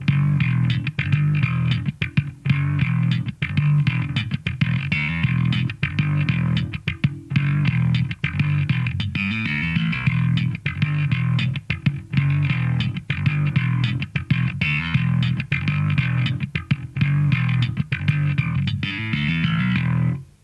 Je to nahráváno na 2 různé basy.